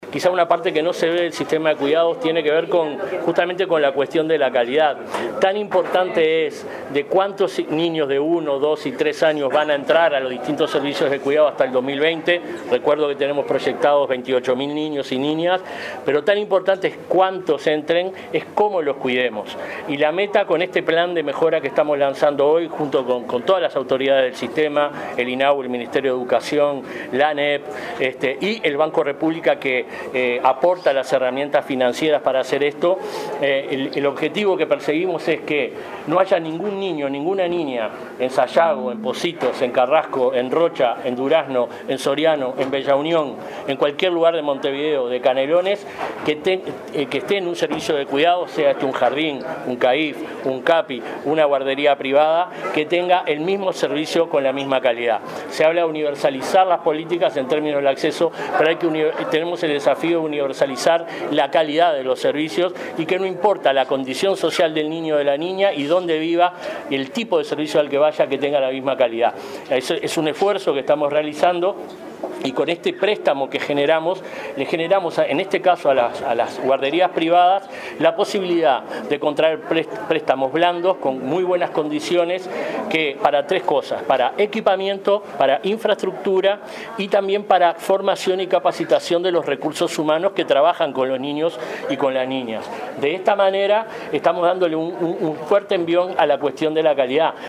Jardines privados que firmaron convenio con el Sistema de Cuidados accederán a créditos para mejorar su infraestructura, equipamiento y la capacitación del personal. República Microfinanzas otorgará préstamos de hasta $ 250.000 en condiciones flexibles. El secretario Julio Bango destacó que la meta es mejorar la calidad de la educación y el cuidado de niños de 0 a 3 años.